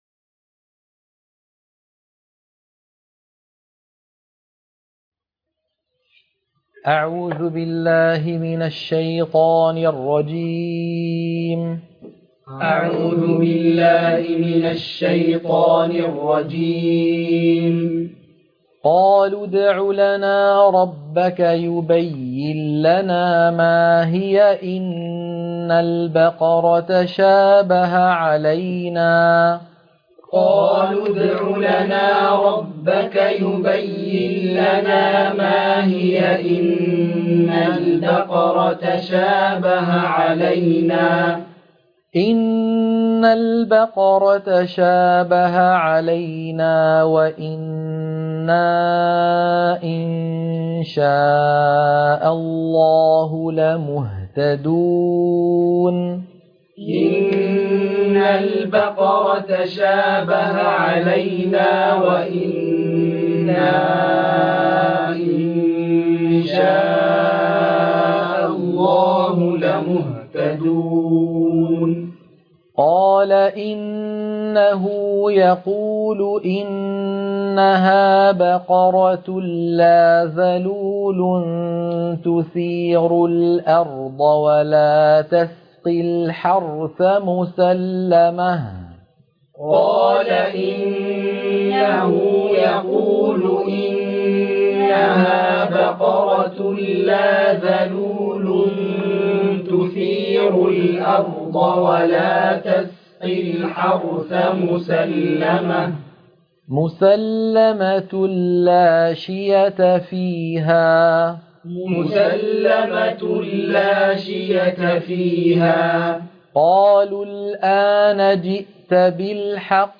القرآن الكريم وعلومه     التجويد و أحكام التلاوة وشروح المتون
عنوان المادة تلقين سورة البقرة - الصفحة 11 _ التلاوة المنهجية